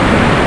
MISSILE.mp3